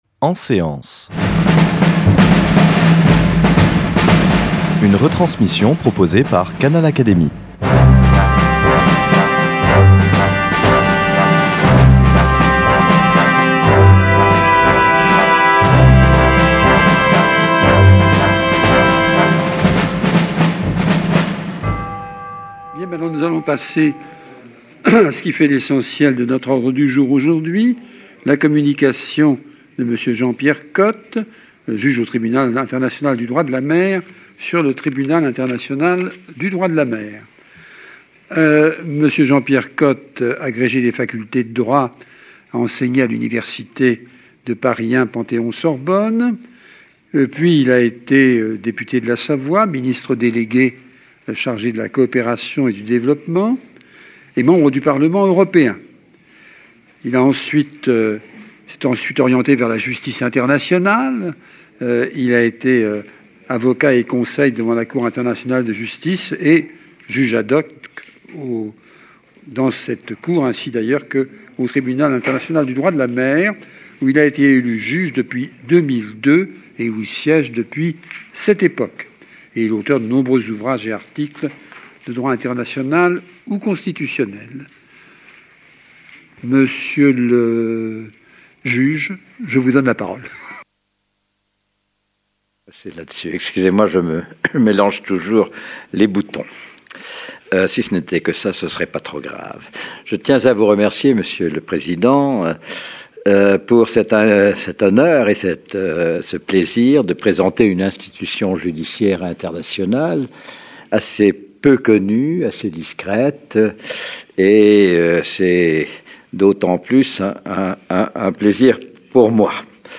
L’orateur a présenté l’histoire et les attributions du Tribunal international du droit de la mer, qui a célébré, en 2016, le vingtième anniversaire de sa mise en place, a été institué par la Convention de Montego Bay sur le droit de la mer de 1982.